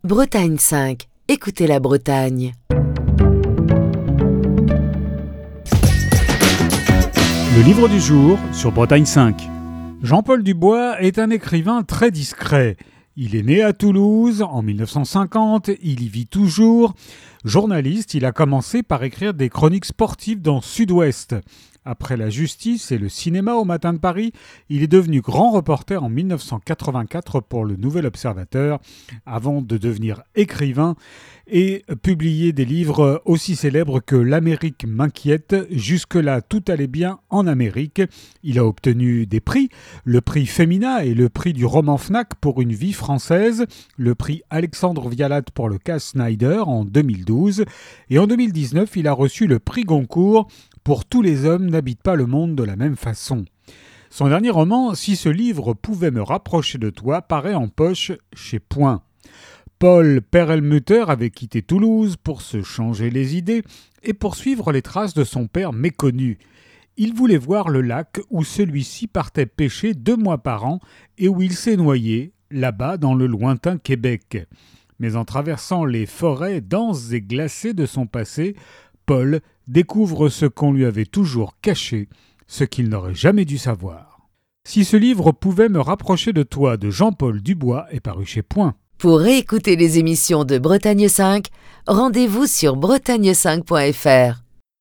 Chronique du 15 décembre 2025.